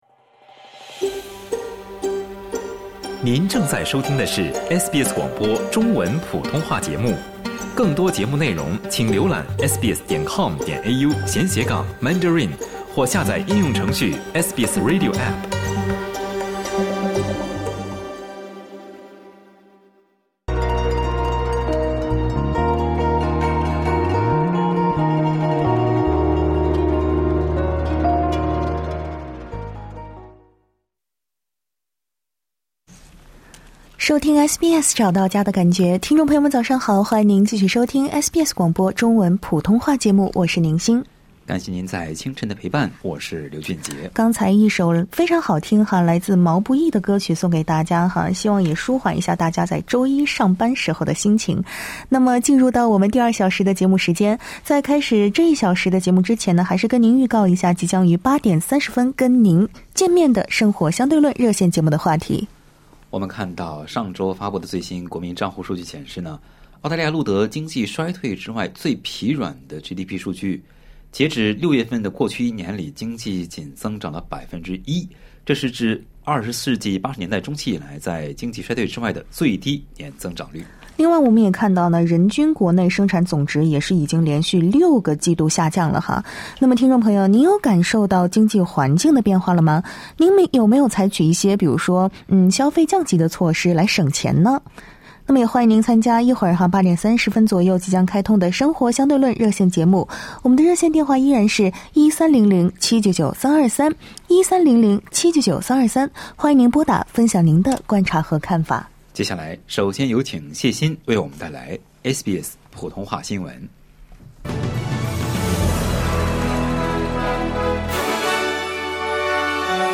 SBS早新闻（2024年9月9日）